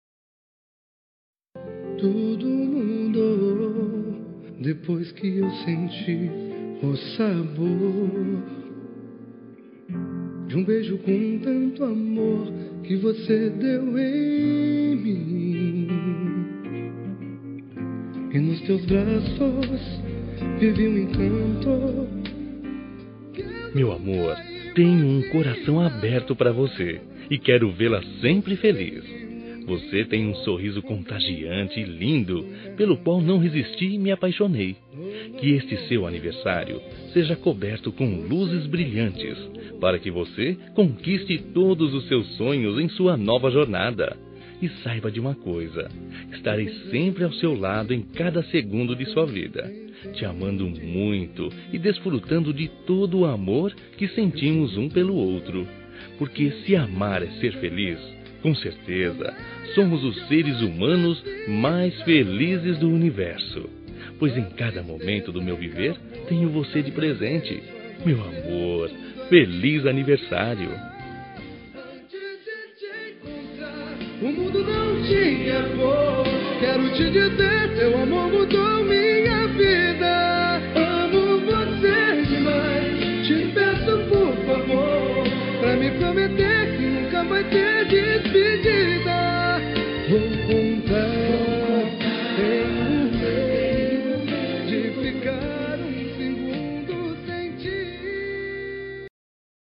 Telemensagem de Aniversário de Esposa – Voz Masculina – Cód: 4045